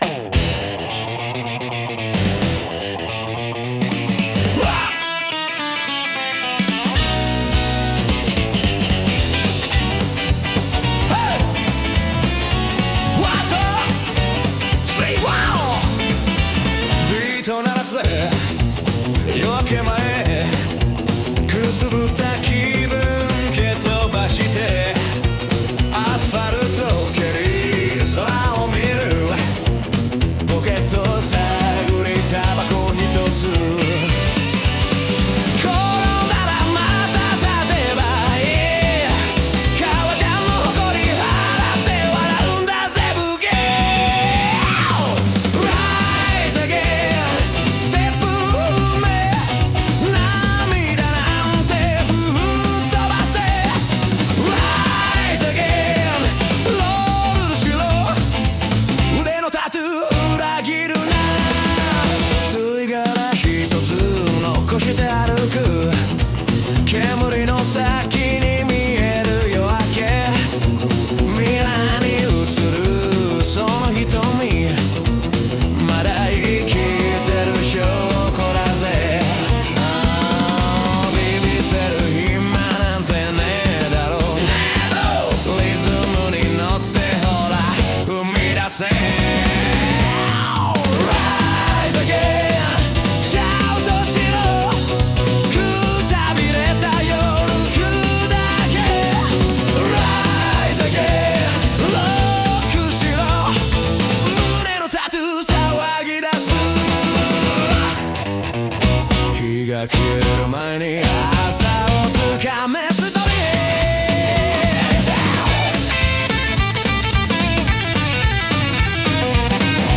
男性ボーカル
イメージ：ロカビリー,ロックンロール,男性ボーカル